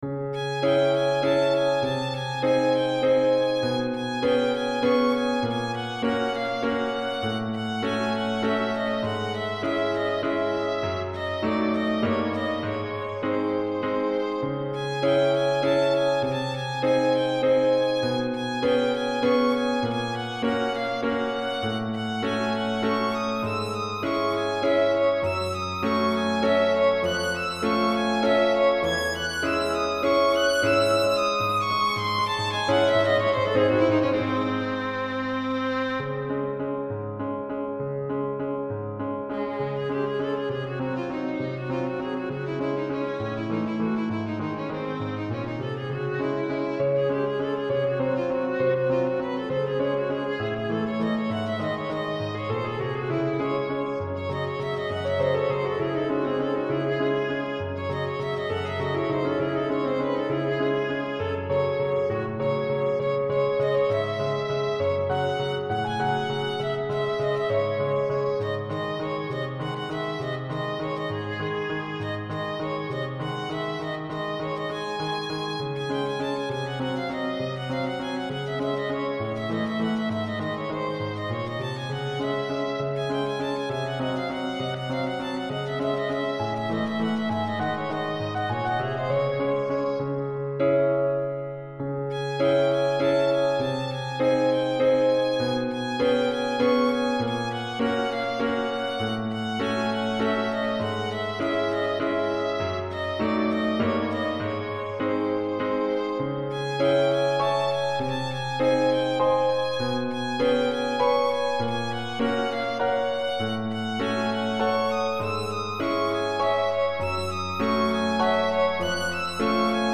TiMidityにて録音(2.65MB)
で、なかなかよいヴァイオリンとピアノの音色が手に入ったので、そいつらのための小さなワルツを作ってみました。
MP3の録音レベルを補正。